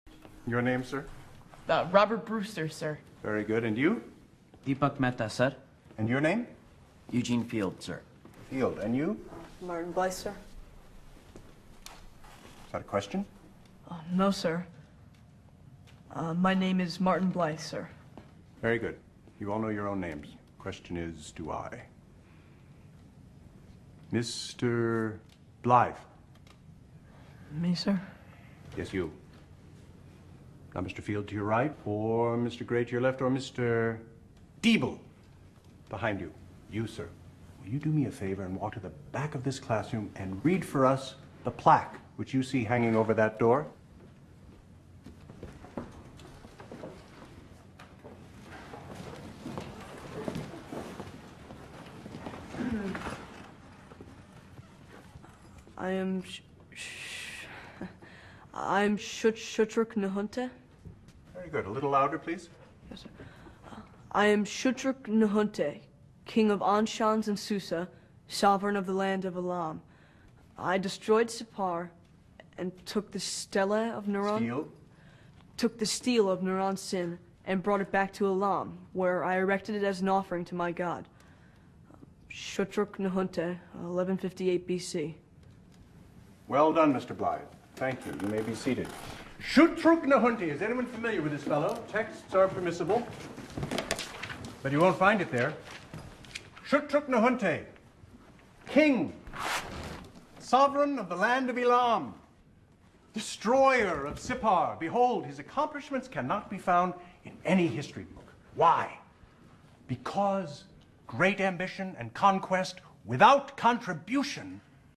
Classics professor William Hundert (Kevin Kline) gives his first history lecture at a liberal arts college.